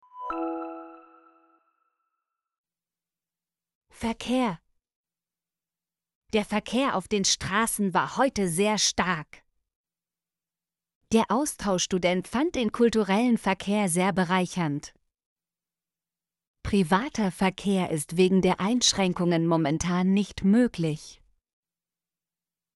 verkehr - Example Sentences & Pronunciation, German Frequency List